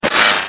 Sounds / Cries